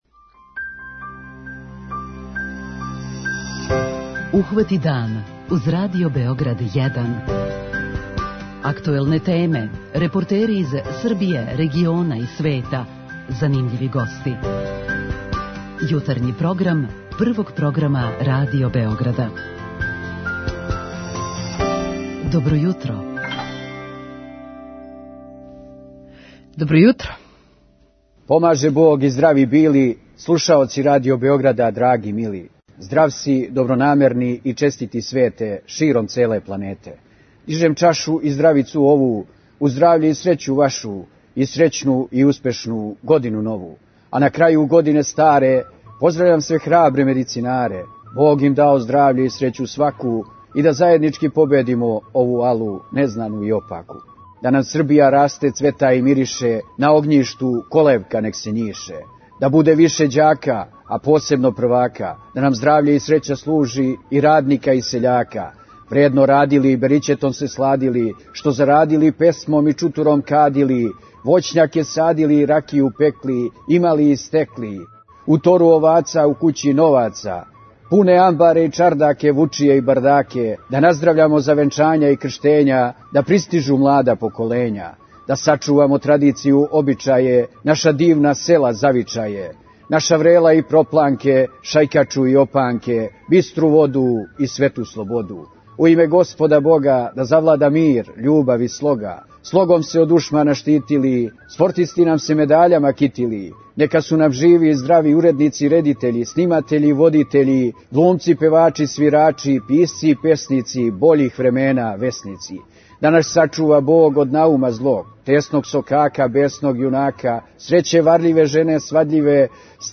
Први јутарњи програм у новој години протећи ће уз сјајан избор народне музике и укључења наших дописника који ће нам пренети како је протекла новогодишња ноћ широм наше земље.